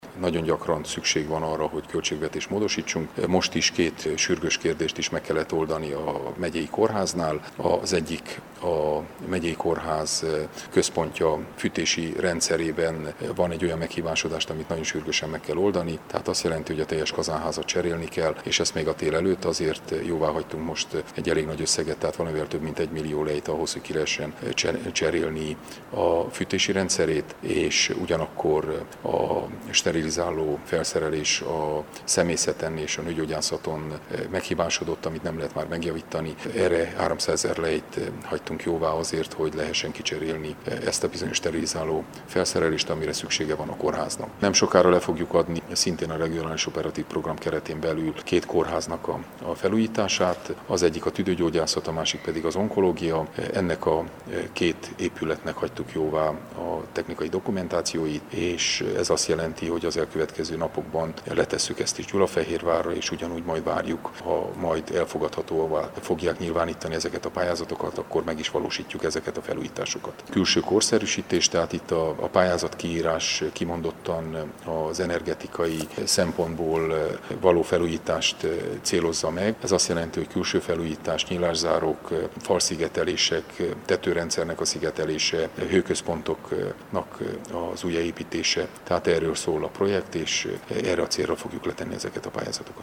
Péter Ferencet tanácselnököt kérdeztük.